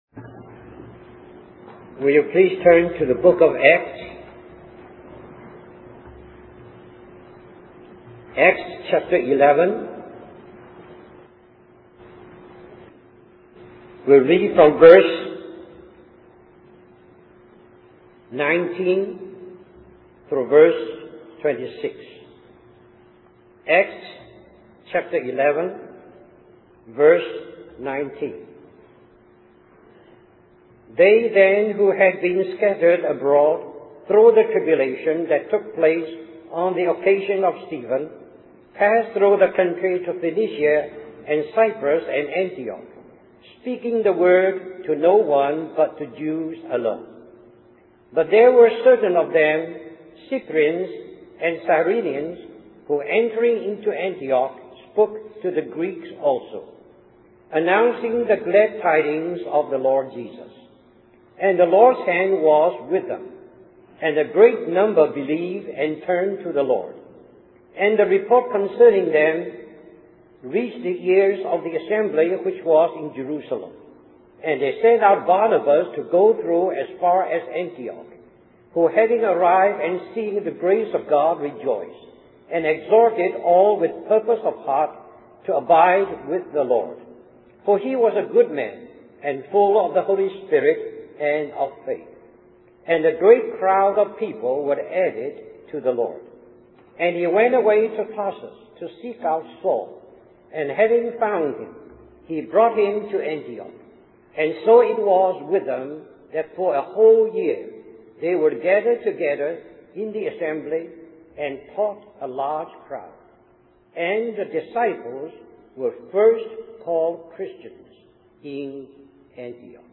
1996 Christian Family Conference Stream or download mp3 Summary In this message